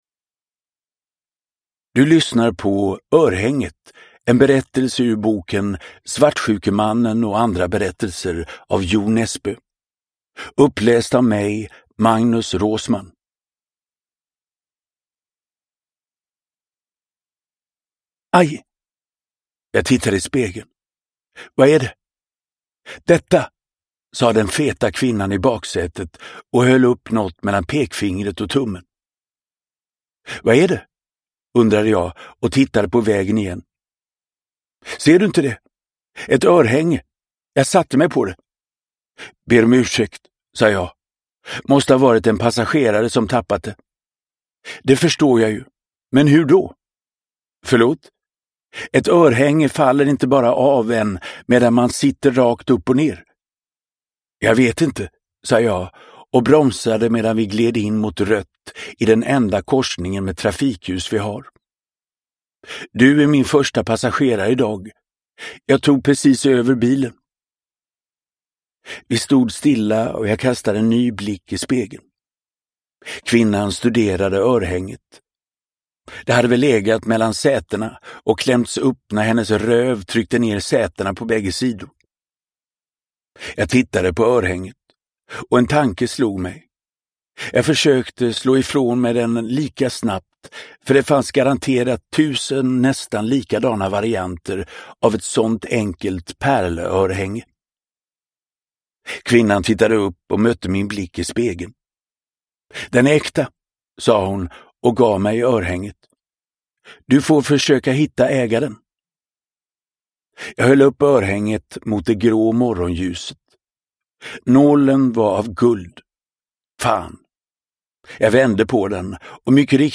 Örhänget : Novell i Svartsjukemannen och andra berättelser – Ljudbok
Uppläsare: Magnus Roosmann